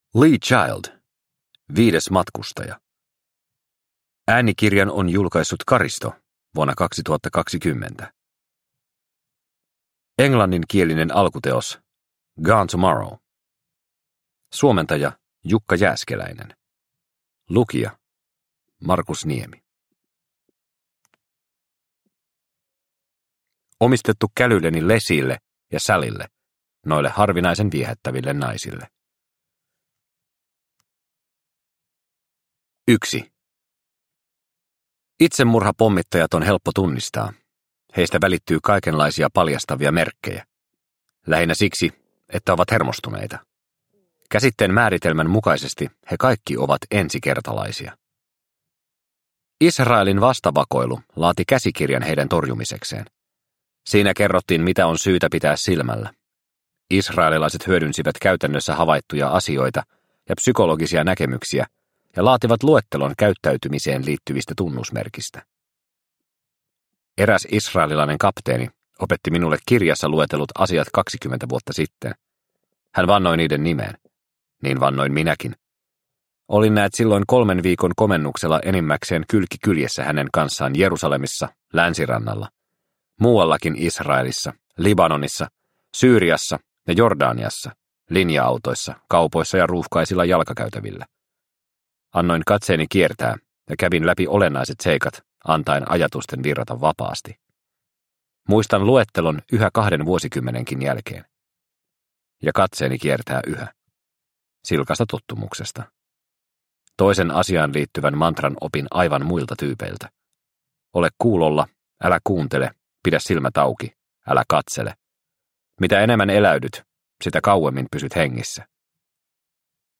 Viides matkustaja – Ljudbok – Laddas ner